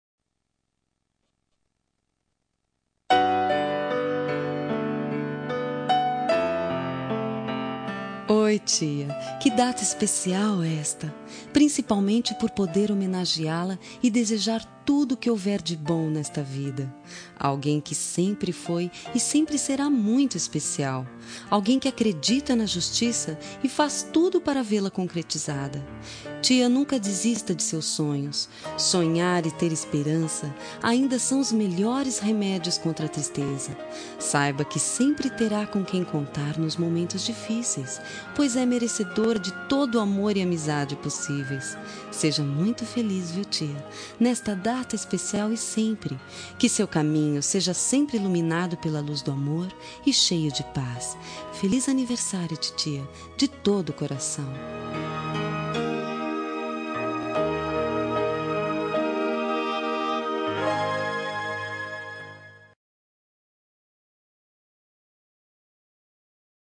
Telemensagem Aniversário de Tia – Voz Feminina – Cód: 2001